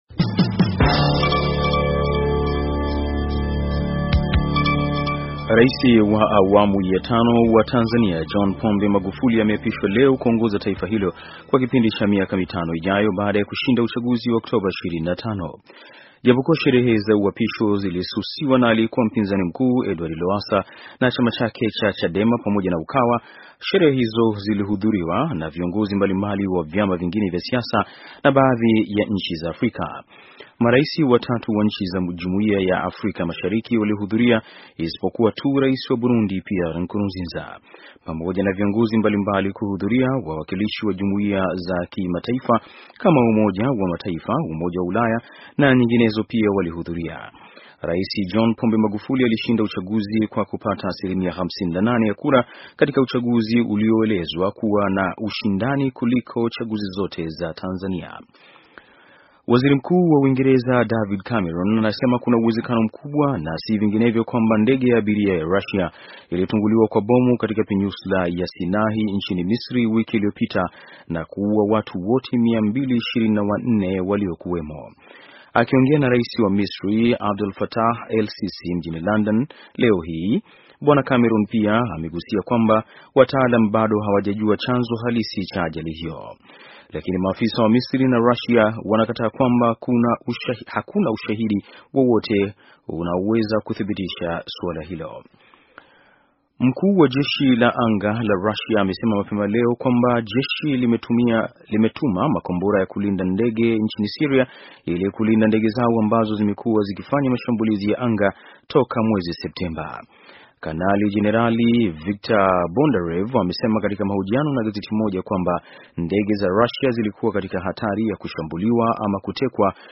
Taarifa ya habari - 4:54